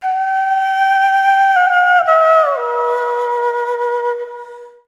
长笛迷你包 " 长笛02